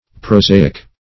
Prosaic \Pro*sa"ic\, Prosaical \Pro*sa"ic*al\, a. [L. prosaius,